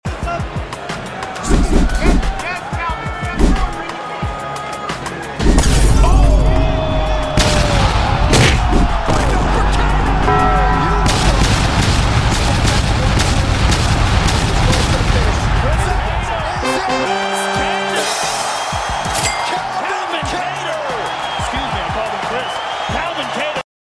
Ufc knockouts w/ sound effects sound effects free download